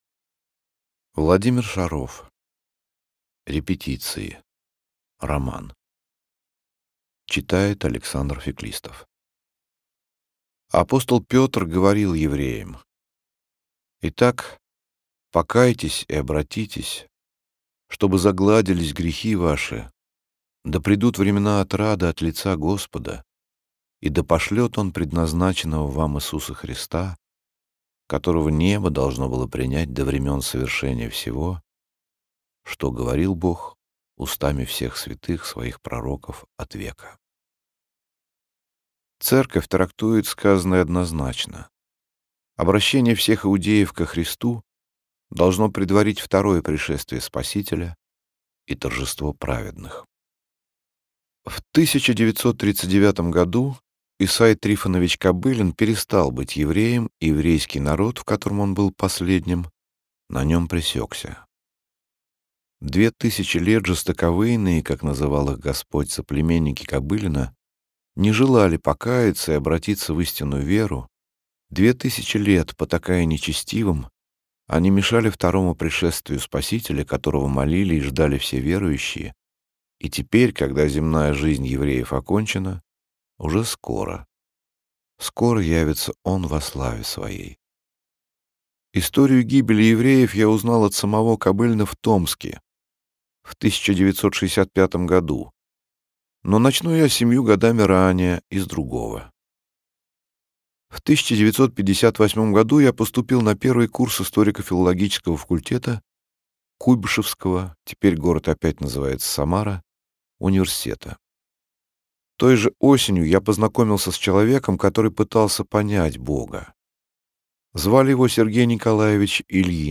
Aудиокнига Репетиции Автор Владимир Шаров Читает аудиокнигу Александр Феклистов.